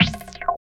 22 CONGAS -L.wav